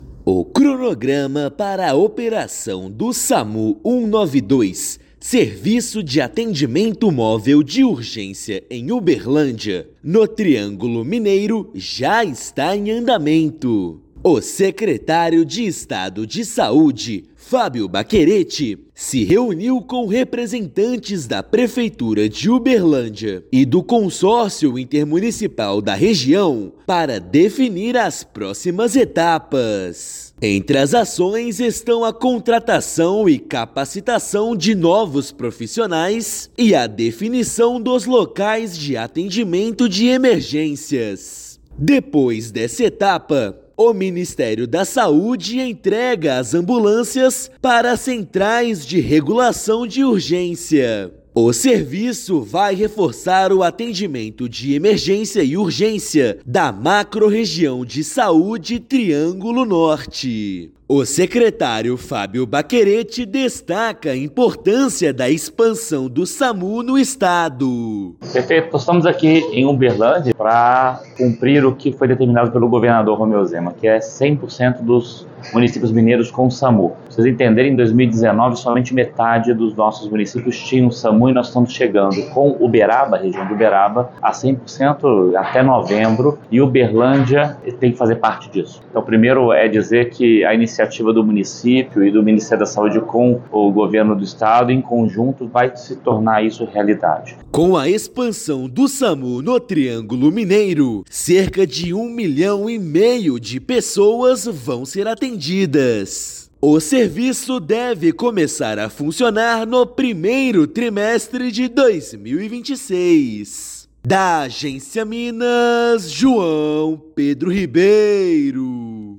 Serviço para atendimento de urgência e emergência tem previsão de início em março de 2026; serão beneficiados 1,5 milhão de habitantes. Ouça matéria de rádio.